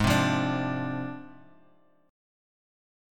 G#6b5 chord